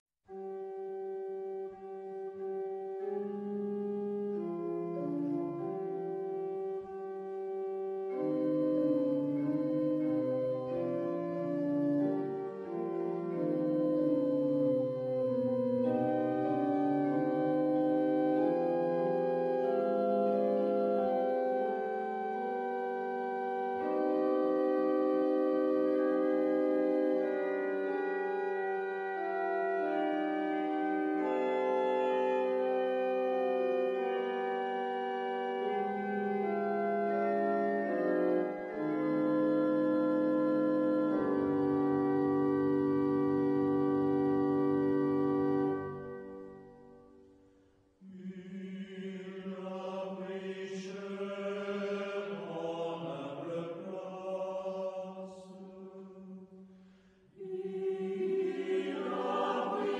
Genre-Stil-Form: Chor ; geistlich ; Hymnus (geistlich)
Chorgattung: SATB  (4 gemischter Chor Stimmen )
Tonart(en): c-moll